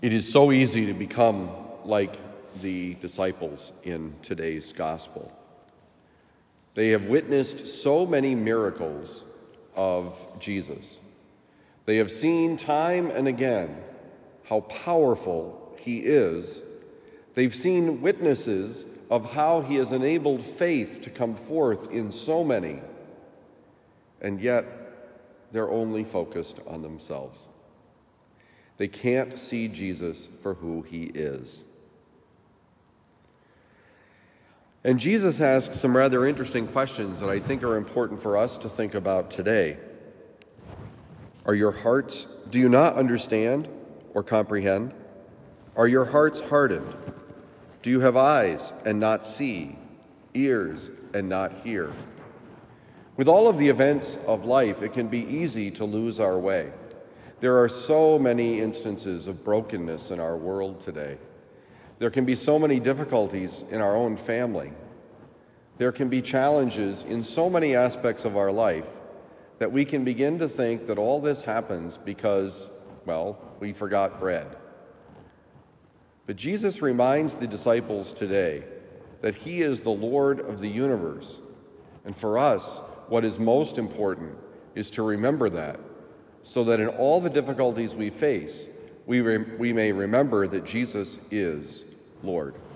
God has the answers: Homily for Tuesday, February 14, 2023
Given at Christian Brothers College High School, Town and Country, Missouri.